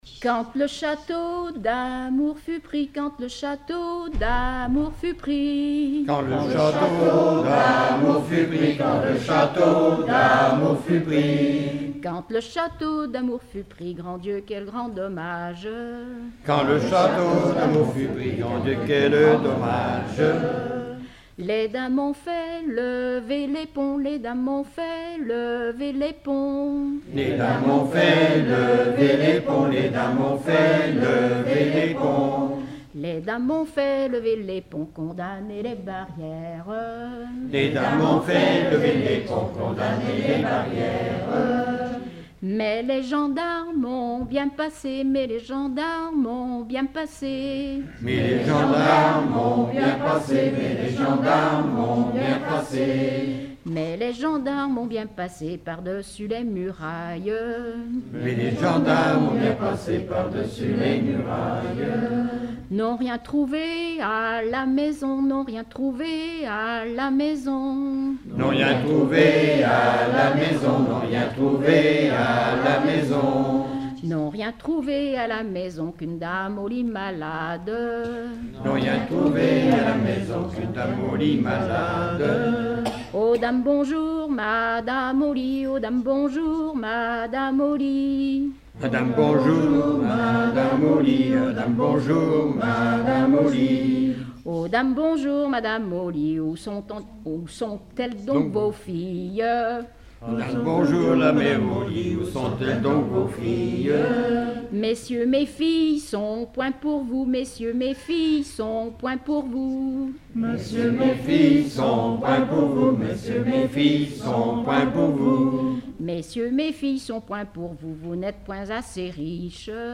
Genre laisse
Collectif-veillée (1ère prise de son)
Pièce musicale inédite